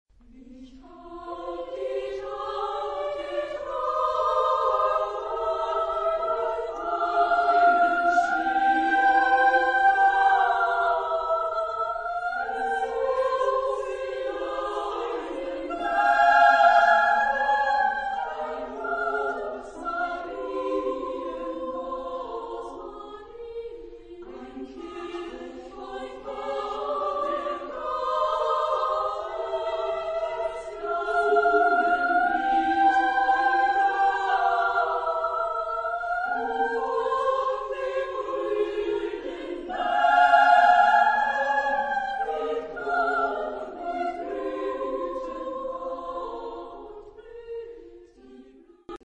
Type of Choir: SSA  (3 women voices )
Discographic ref. : 7. Deutscher Chorwettbewerb 2006 Kiel